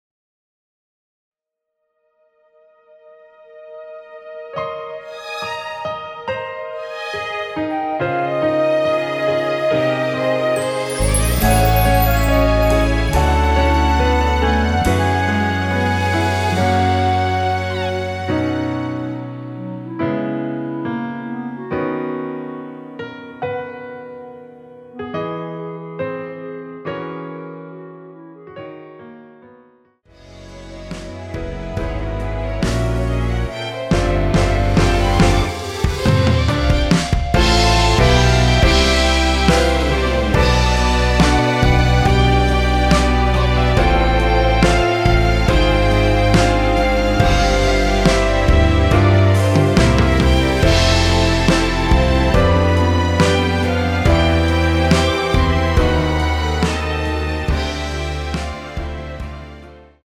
원키에서(+1)올린 멜로디 포함된 MR입니다.(미리듣기 확인)
앞부분30초, 뒷부분30초씩 편집해서 올려 드리고 있습니다.
곡명 옆 (-1)은 반음 내림, (+1)은 반음 올림 입니다.
(멜로디 MR)은 가이드 멜로디가 포함된 MR 입니다.